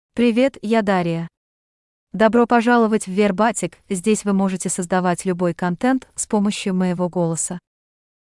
Dariya — Female Russian AI voice
Dariya is a female AI voice for Russian (Russia).
Voice sample
Listen to Dariya's female Russian voice.
Female